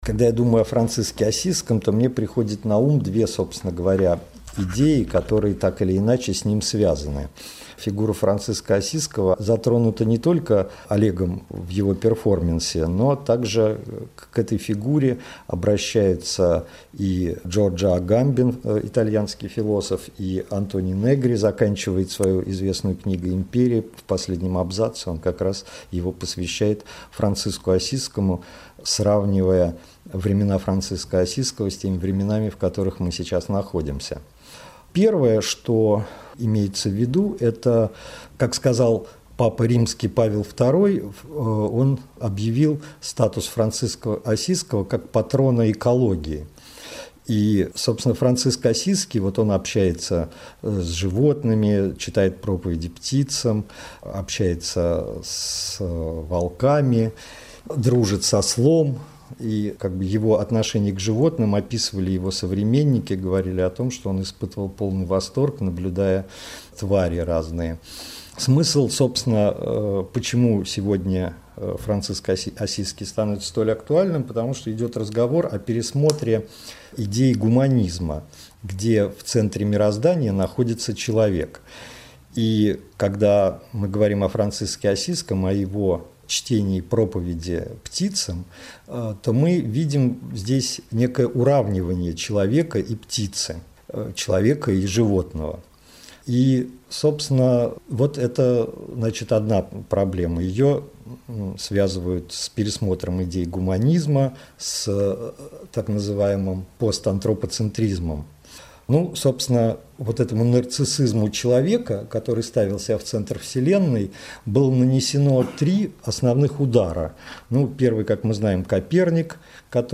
speaks with Oleg Kulik